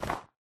snow4.ogg